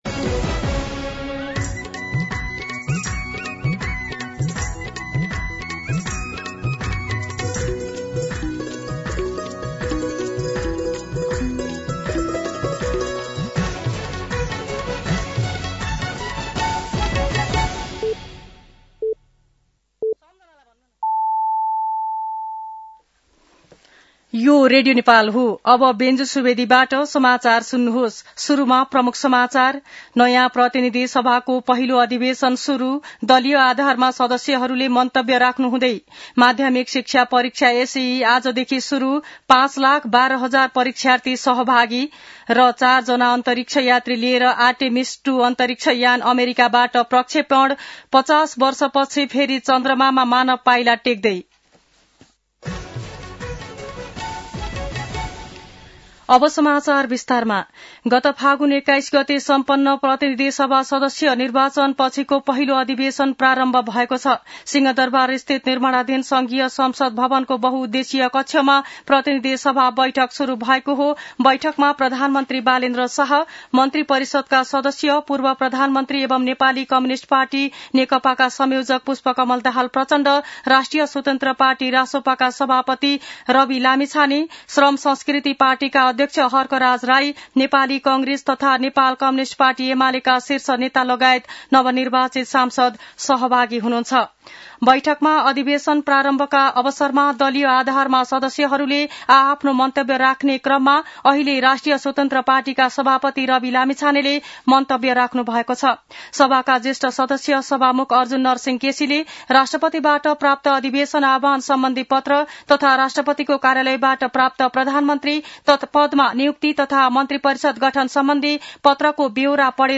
दिउँसो ३ बजेको नेपाली समाचार : १९ चैत , २०८२